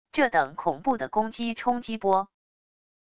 这等恐怖的攻击冲击波wav音频